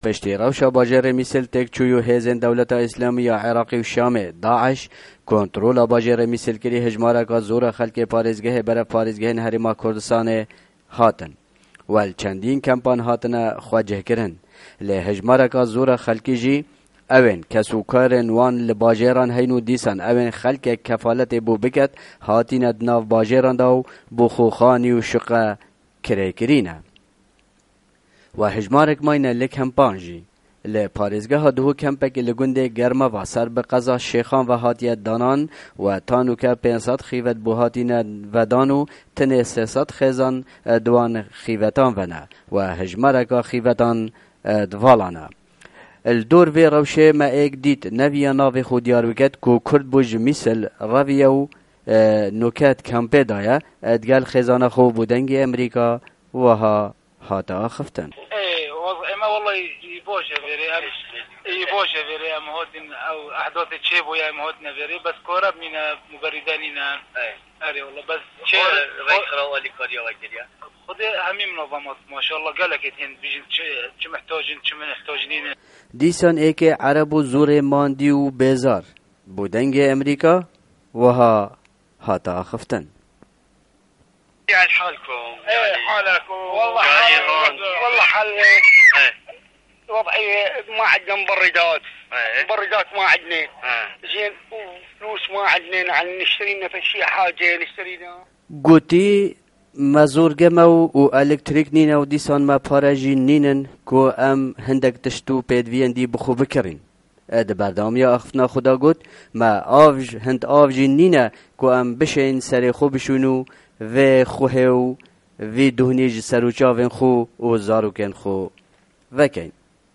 Raporteke Taybet ya ser Penaberên ku Musilê Revîne